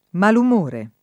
malum1re] s. m. — antiq. mal umore [id.] (non mal’umore), col pl. mali umori [